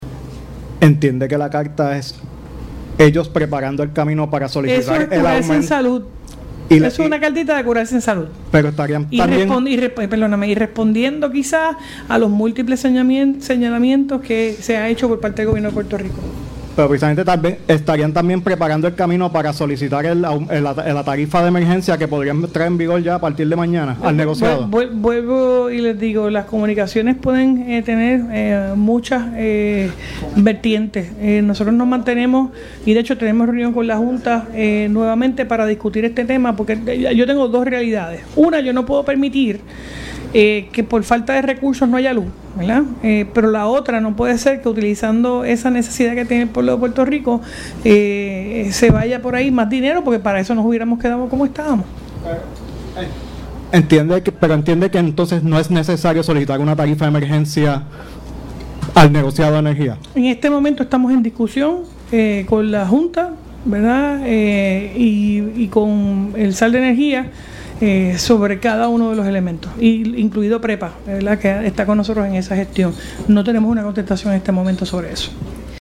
A preguntas de la prensa en una conferencia sobre si entendía que es una manera en la que LUMA está preparando el camino para solicitar un aumento o la llamada tarifa de emergencia, González Colón aseguró que “todo el mundo tiene sus deseos en la vida“, dijo en referencia al pedido al destacar que el Gobierno hizo un mal negocio con la empresa.
324-JENNIFFER-GONZALEZ-GOBERNADORA-EN-DISCUSION-CON-LA-JSF-PREPA-AUMENTO-EN-LA-FACTURA-DE-LA-LUZ-1.mp3